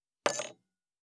247,食器にスプーンを置く,ガラスがこすれあう擦れ合う音,グラス,コップ,工具,小物,雑貨,コトン,トン,ゴト,ポン,ガシャン,
コップ効果音厨房/台所/レストラン/kitchen物を置く食器